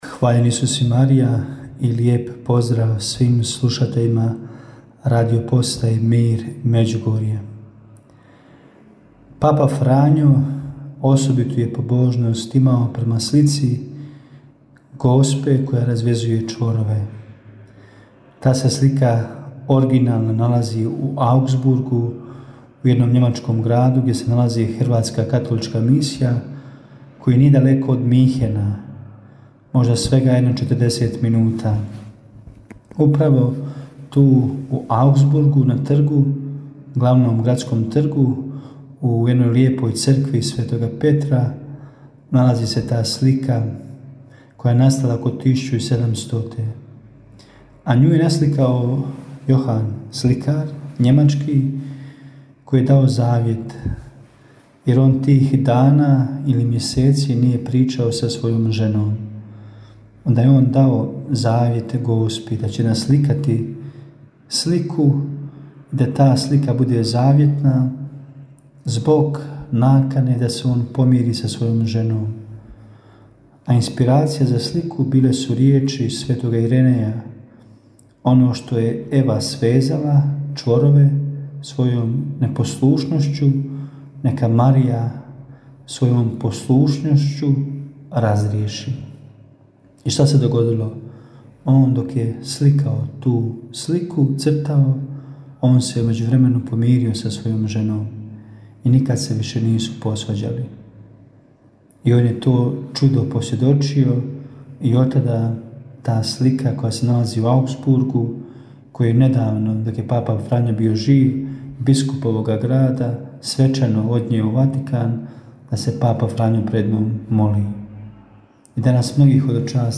U njoj na pitanja slušatelja odgovaraju svećenici, suradnici Radiopostaje Mir Međugorje.